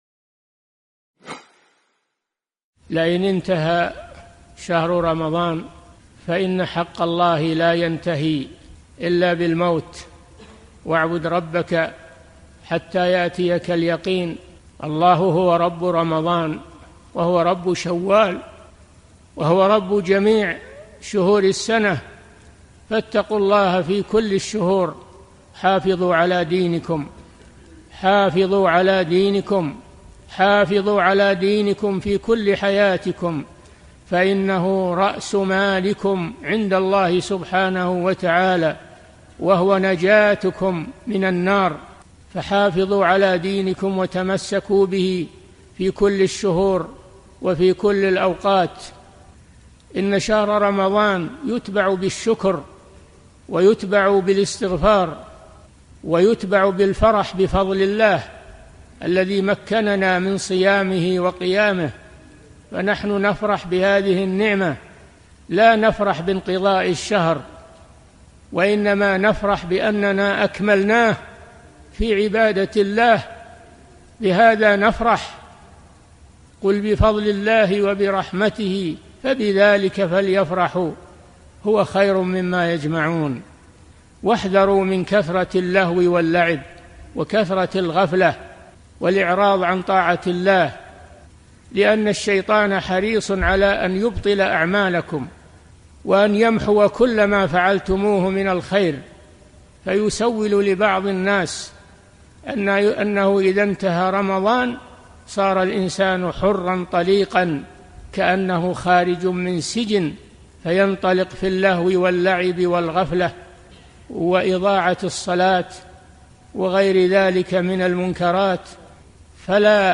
Λόγια νουθεσίας και υπενθύμισης από τον Σέηχ Σάλιχ αλ Φαουζάν حفظه الله σχετικά με την κατάσταση του μουσουλμάνου μετά τον Ραμαντάν. Ο Σέηχ τονίζει ότι η υπακοή προς τον Αλλάχ δεν περιορίζεται σε έναν μήνα, αλλά είναι διαρκής υποχρέωση μέχρι τον θάνατο, όπως αναφέρει και ο λόγος του Αλλάχ στο Κοράνι (15:99).
ramadan-end-fawzan-nashiha.mp3